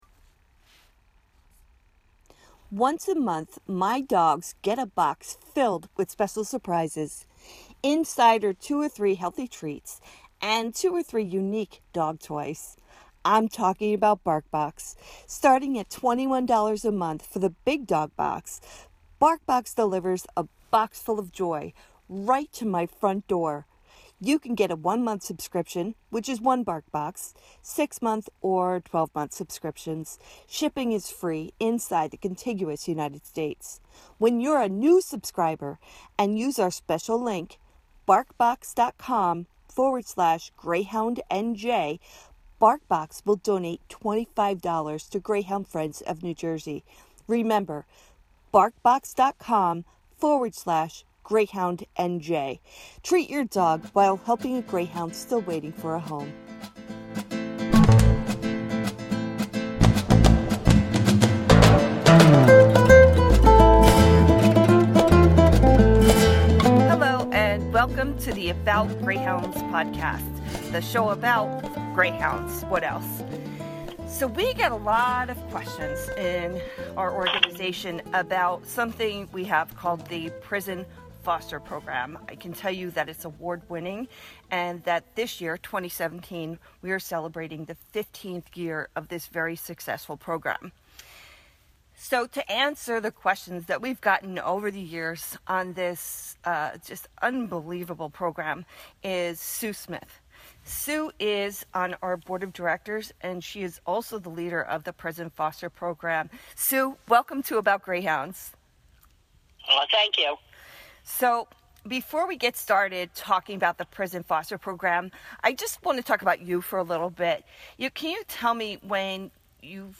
In this episode, hear a conversation